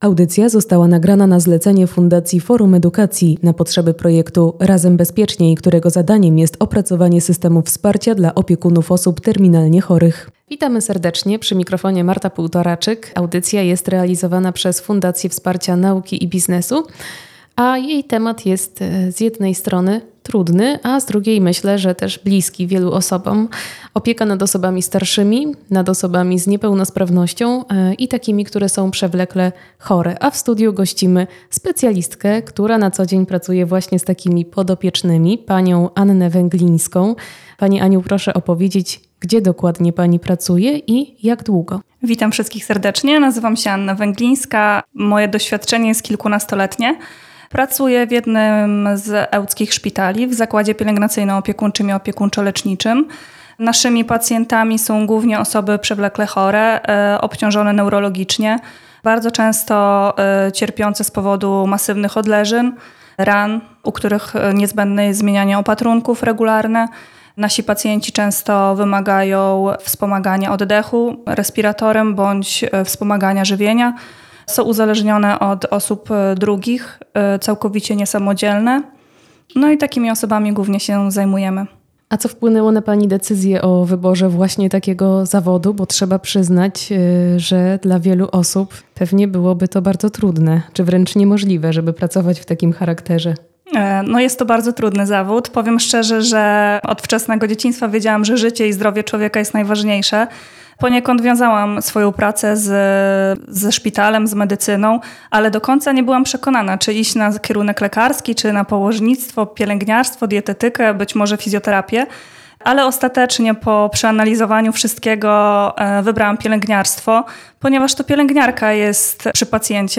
Poniżej przedstawiamy nagrania z wywiadu z opiekunami
Wywiad do warsztatu – przygotowanie PERSON do metody DESIGN THINKING